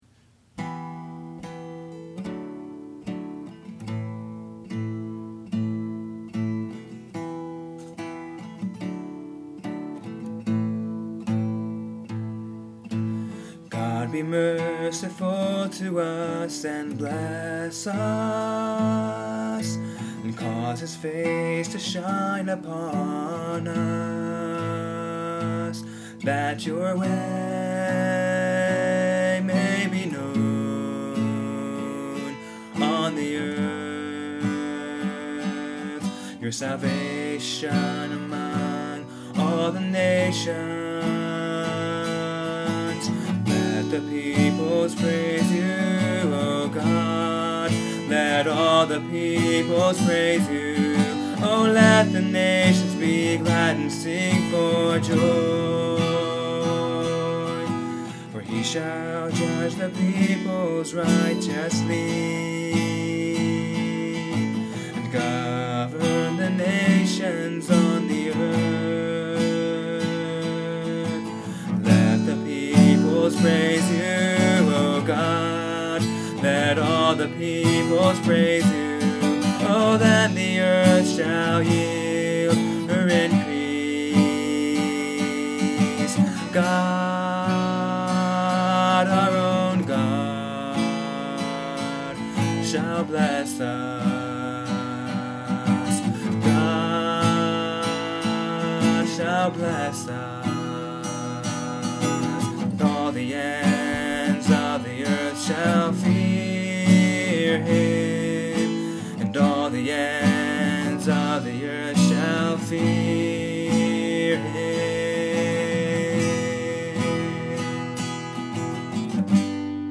I like its mellow mood.